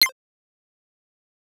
チップチューン・サンプルパック
チップチューン音楽の制作などでお使い頂ける8bit風のサンプルです。
8bit FX
8bit_FX_C_01_01.wav